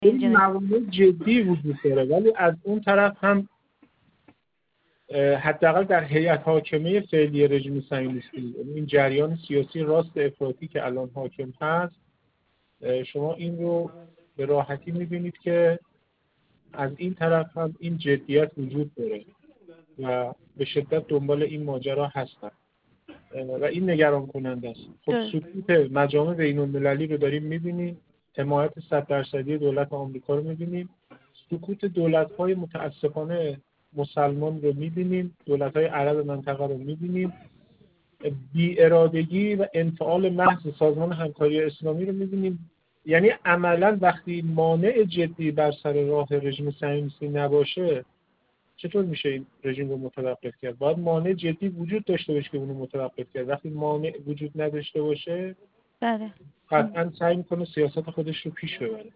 کارشناس مسائل فلسطین و رژیم صهیونیستی